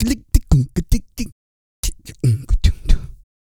EH REGGAE 2.wav